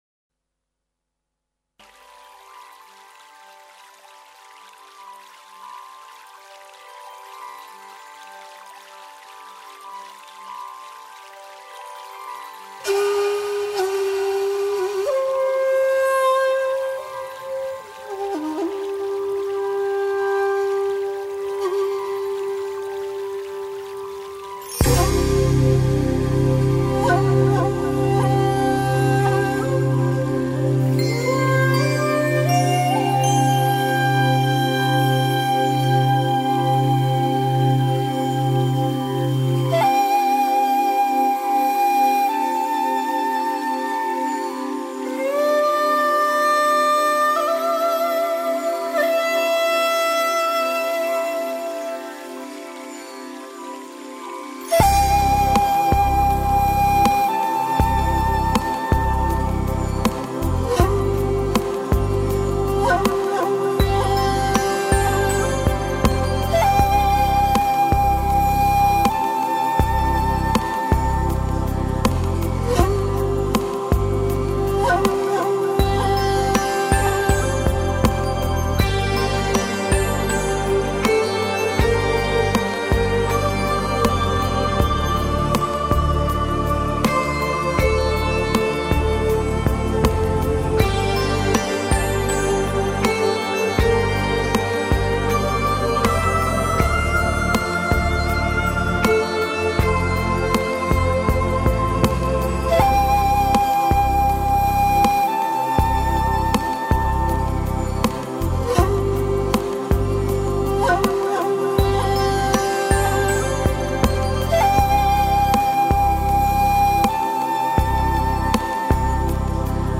灵感和情绪完全渗入到音乐中：其间包括诗诵，圣经的引用，流行声乐，优美的萨克斯，合成器迷人的旋律线条和充满了银杏梦幻色彩。